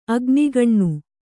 ♪ agnigaṇṇu